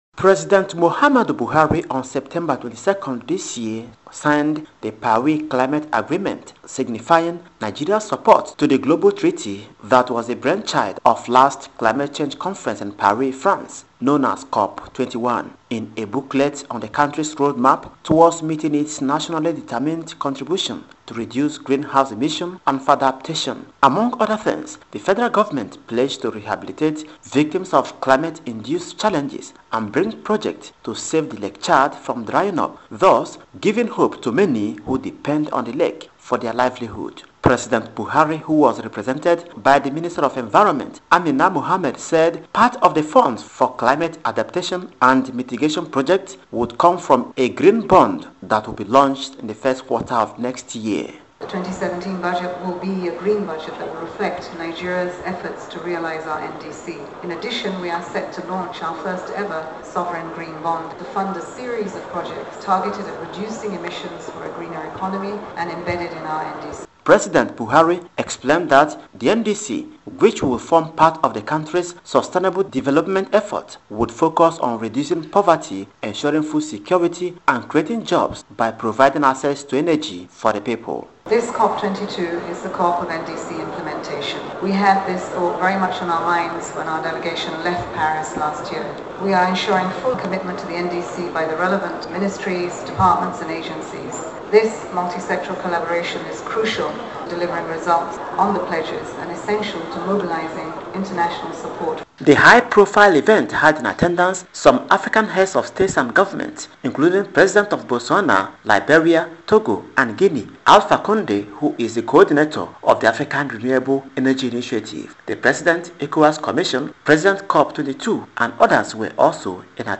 Radio Report: Nigeria to Implement NDC with Sovereign Green Bond – Buhari